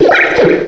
cry_not_cottonee.aif